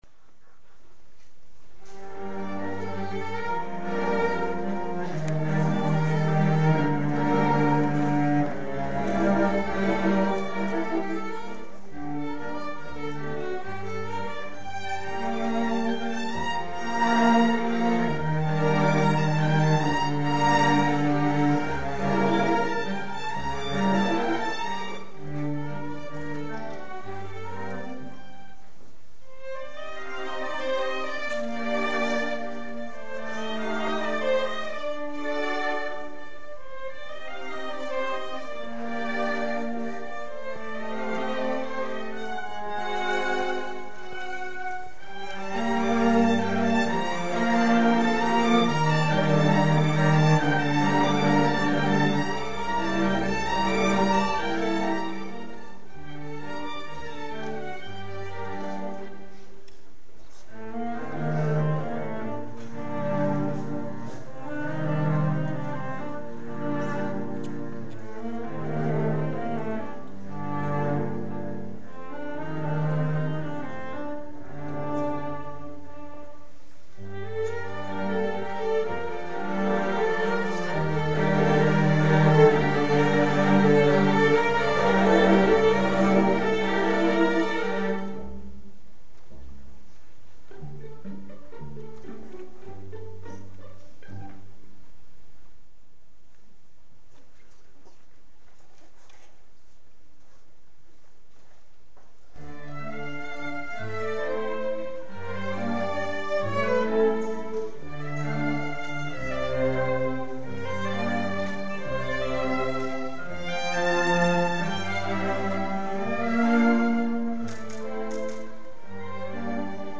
for string orchestra (per orchestra d'archi)